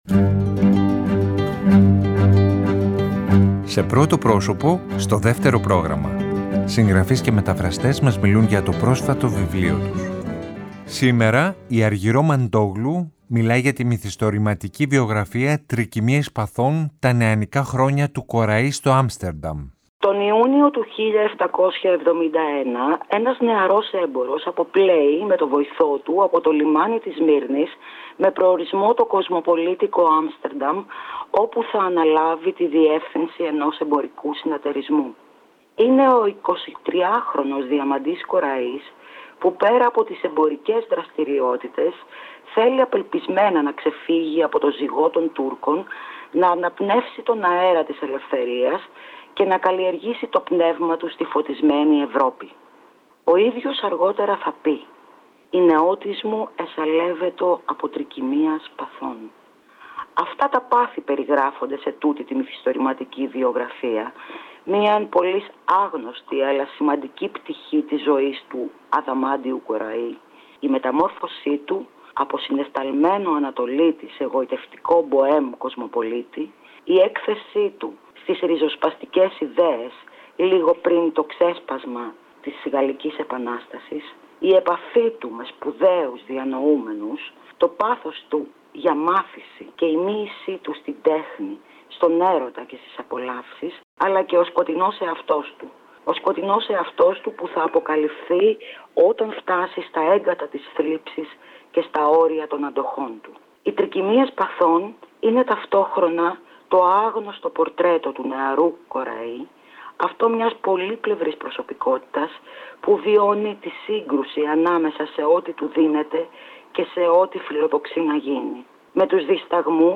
Συγγραφείς και μεταφραστές μιλάνε